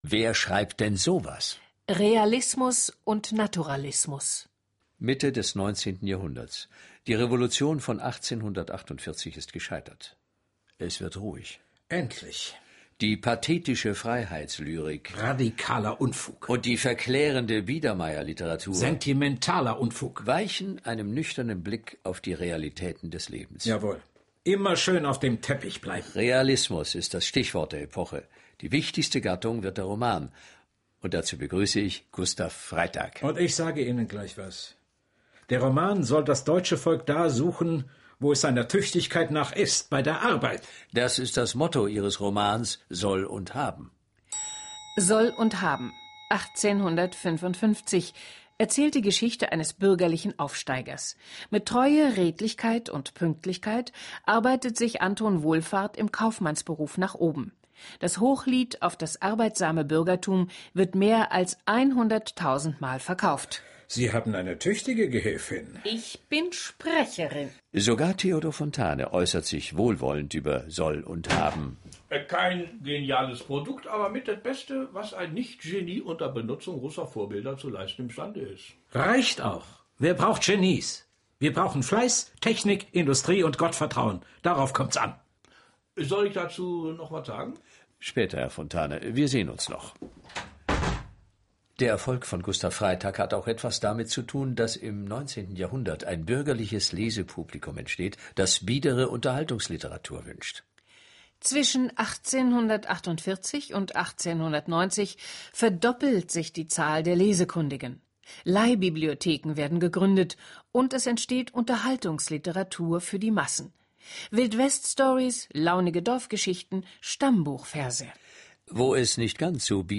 Die wichtigsten Bücher der neueren deutschen Literaturgeschichte werden in Dichter-Dialogen, Spielszenen und Originalzitaten pointenreich präsentiert. Nach langen Jahrhunderten, in denen nur blaublütige oder antik gewandete Helden echter Tragik und der epischen und dramatischen Schilderung würdig waren, entstehen in den realistischen Romanen Panoramabilder der bürgerlichen Gesellschaft.